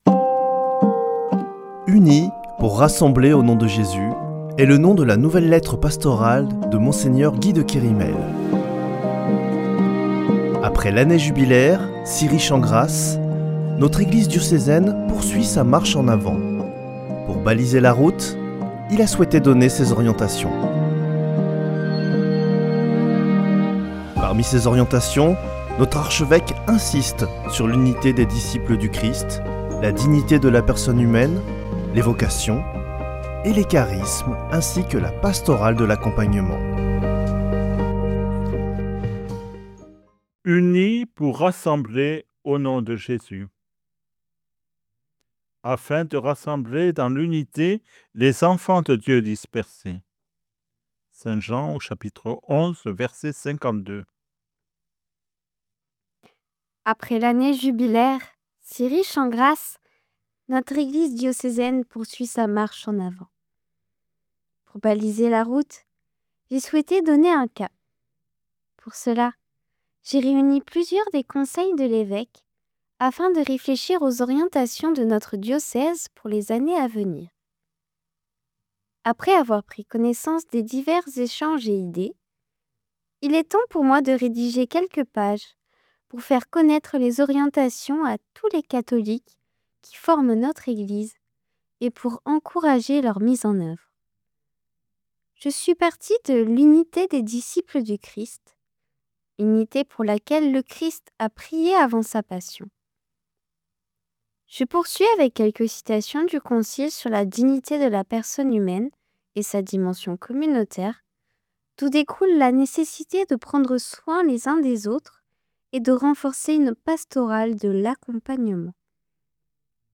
Une émission présentée par Mgr Guy de Kerimel Archevêque de Toulouse